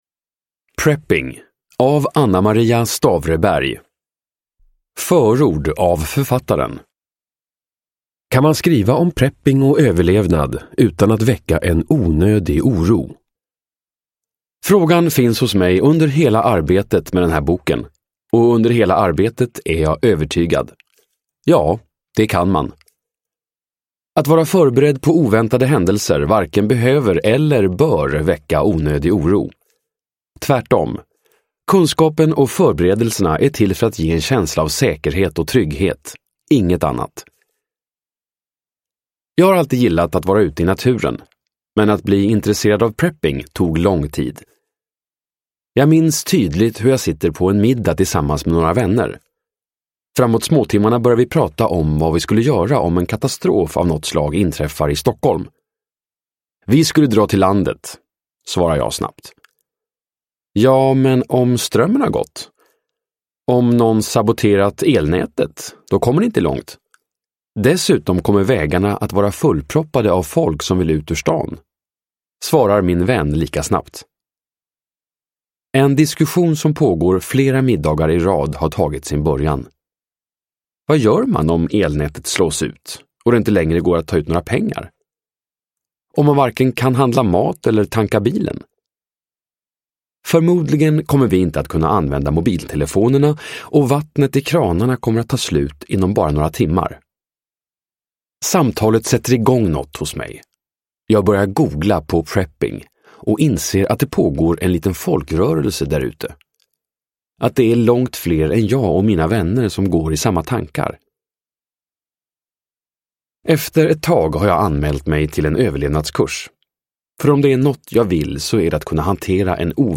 Prepping : att hoppas på det bästa, men förbereda sig på det värsta (ljudbok